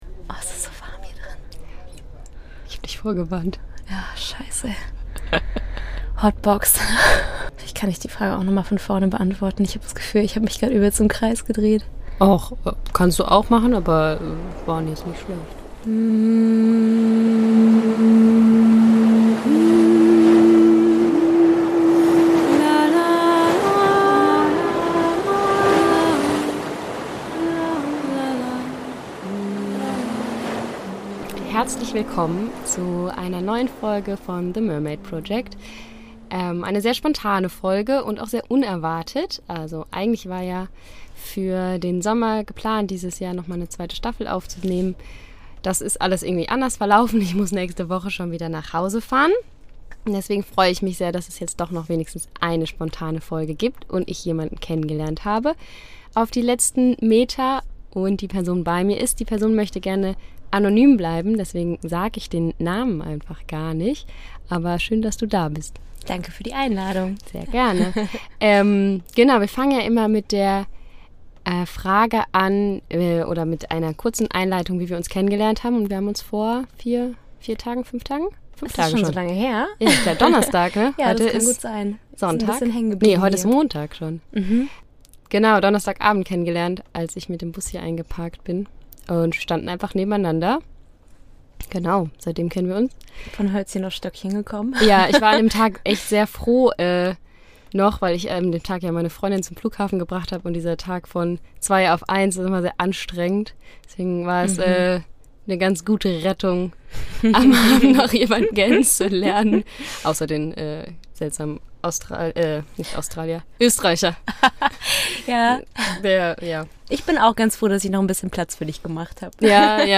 Beschreibung vor 2 Jahren Zwischen flüchten und finden: mit meiner Van-Nachbarin spreche ich in dieser Folge über die Erfahrungen und Erlebnisse auf einer Van-Reise allein als Frau.